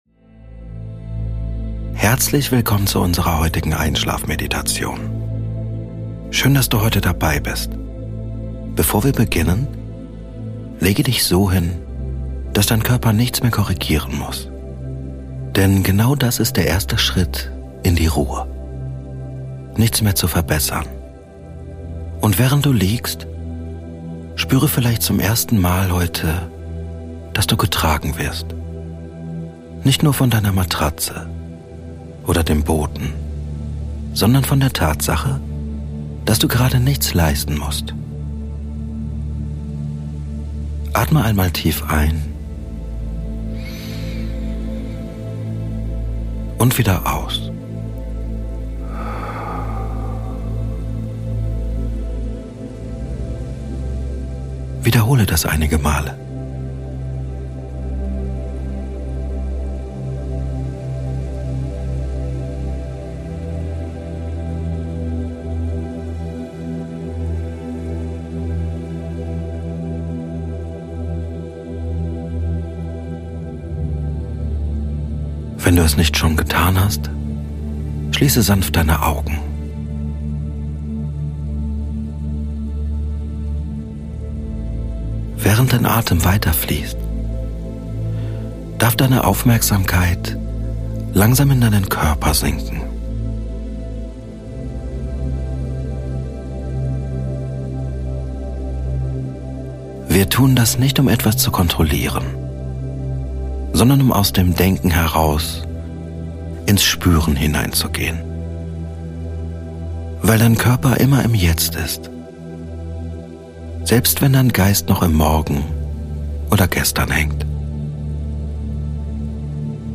Diese Folge ist eine ruhige, geführte Einschlafmeditation, die dich dabei unterstützt, den Tag hinter dir zu lassen und auf sanfte Weise in den Schlaf zu finden.
Mit langsamer Sprache, einem gleichmäßigen Tempo und einer beruhigenden Traumreise entsteht ein entspannter Raum, in dem Gedanken leiser werden und innere Anspannung nachlassen darf.